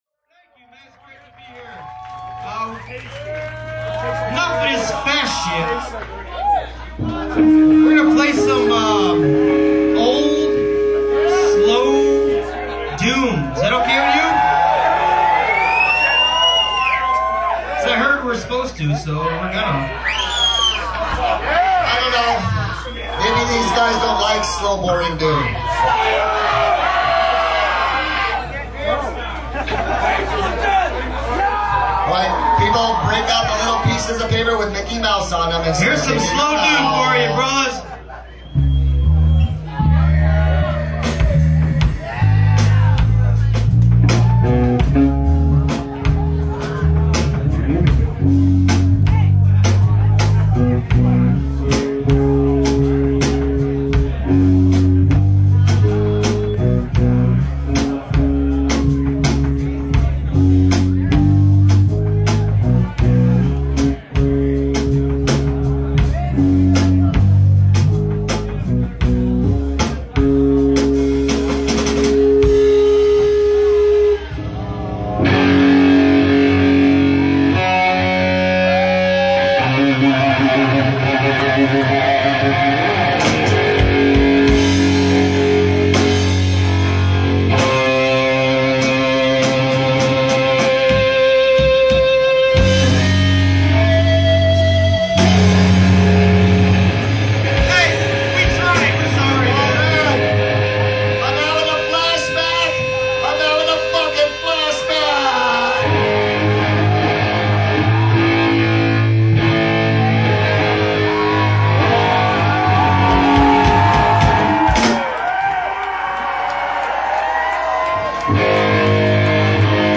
"Live on Tape"
Nyabinghi - Youngstown, OH